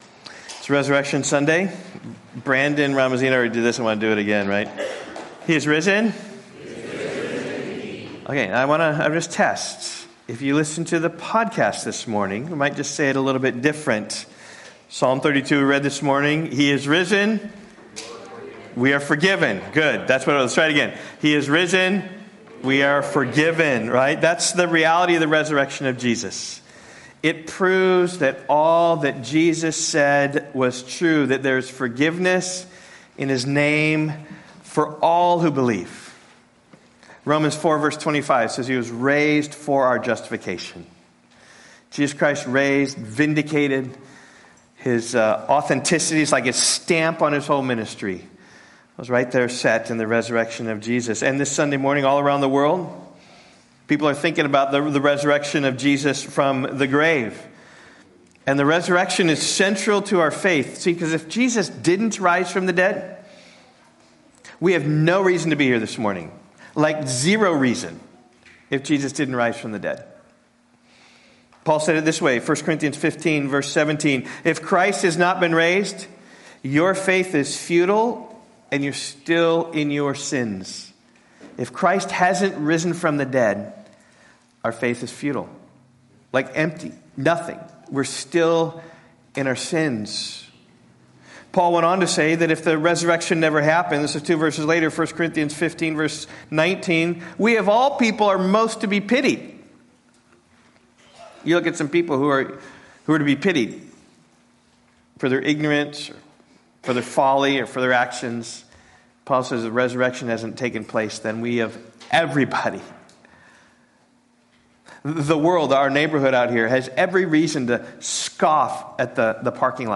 Sermon audio from Rock Valley Bible Church.